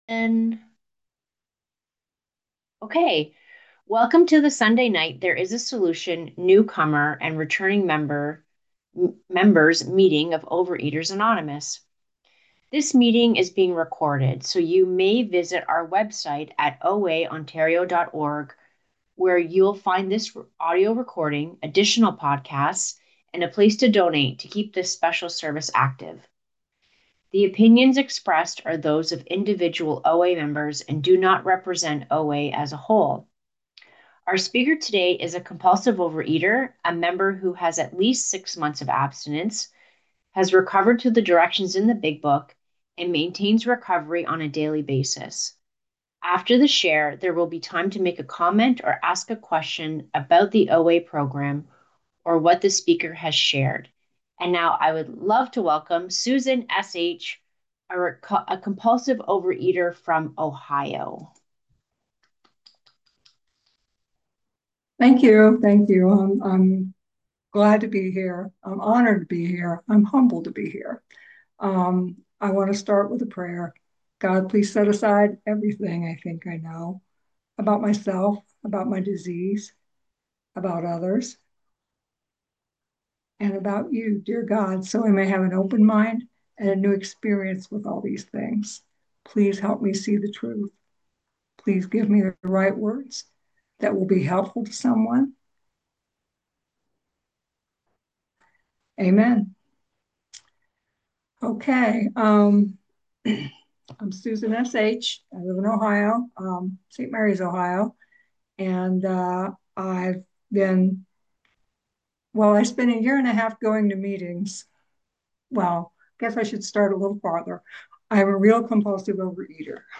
Overeaters Anonymous Central Ontario Intergroup Speaker Files OA Newcomer Meeting